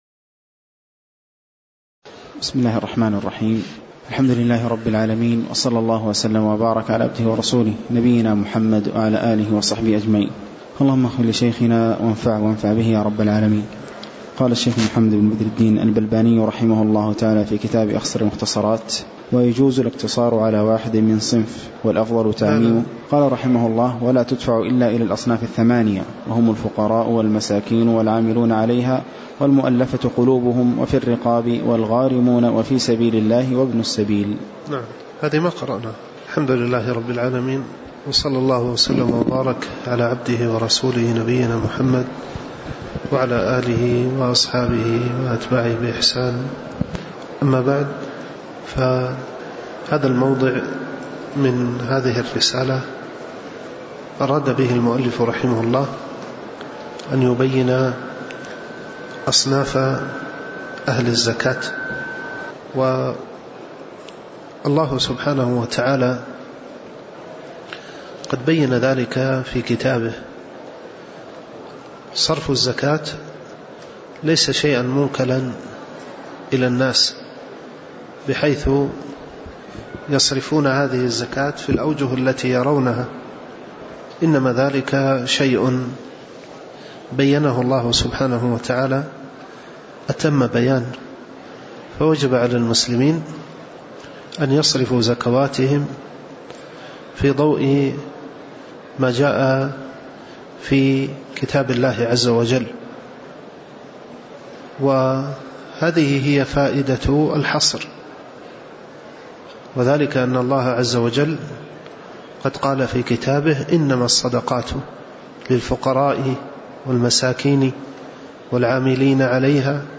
تاريخ النشر ٢٩ محرم ١٤٤٠ هـ المكان: المسجد النبوي الشيخ